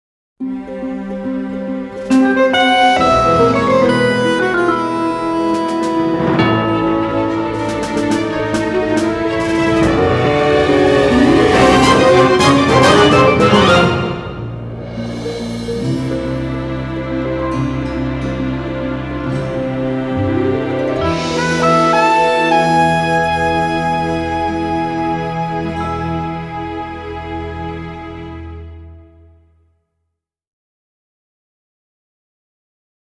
soprano sax